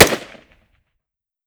fps_project_1/5.56 M4 Rifle - Gunshot A 005.wav at d65e362539b3b7cbf77d2486b850faf568161f77